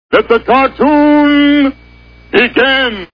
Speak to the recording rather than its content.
Pee Wee's Play House TV Show Sound Bites